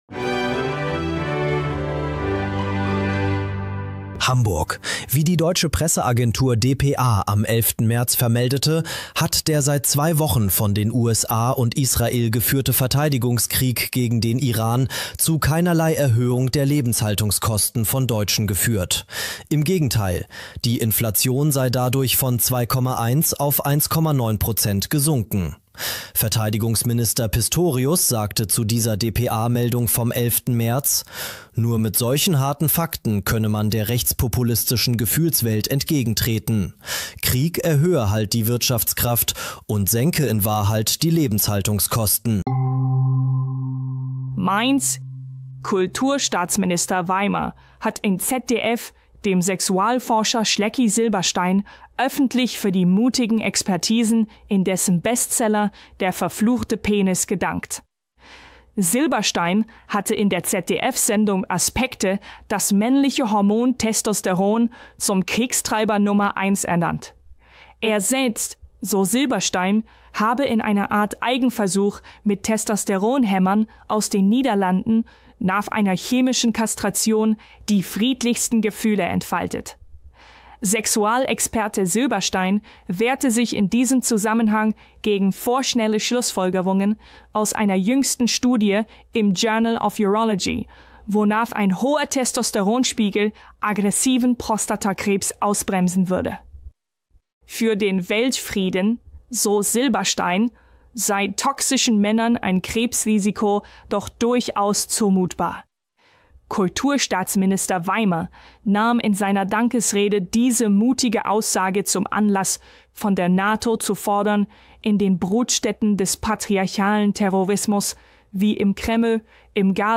Alle Mitglieder und einige G�ste feierten gemeinsam.